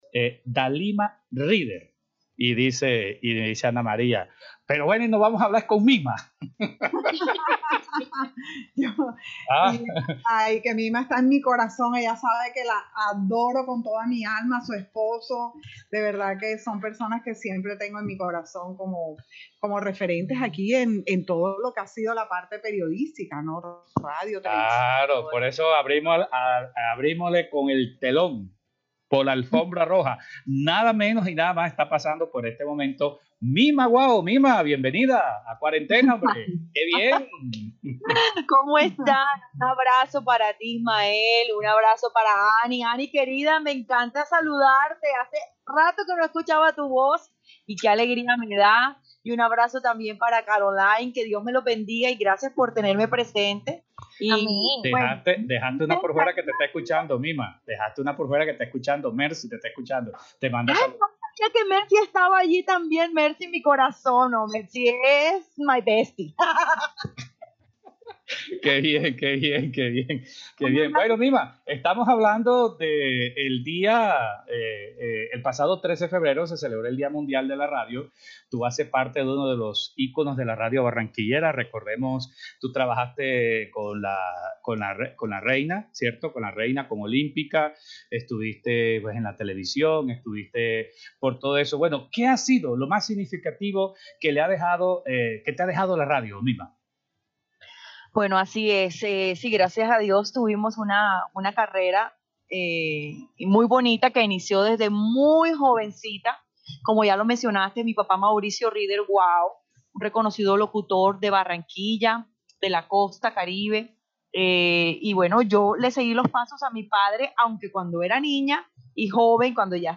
En conversación con el programa Cuarentena del Sistema Cardenal